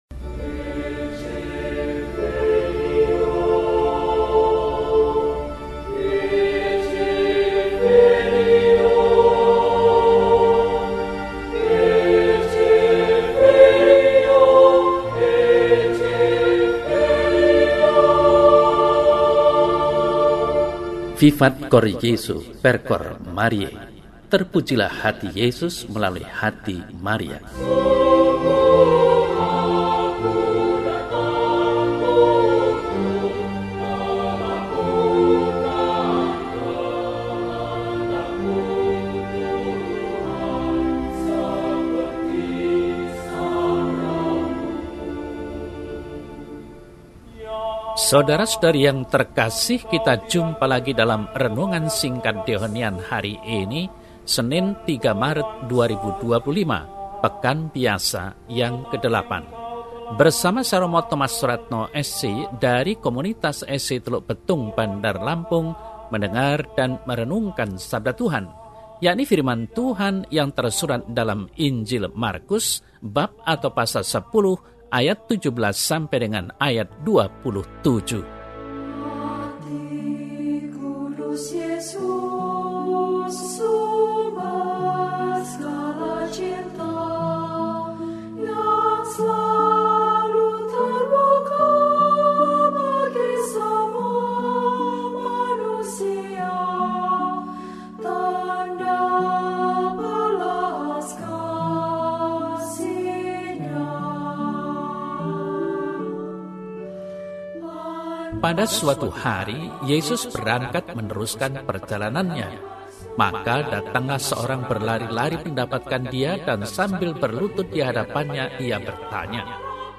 Senin, 03 Maret 2025 – Hari Biasa Pekan VIII – RESI (Renungan Singkat) DEHONIAN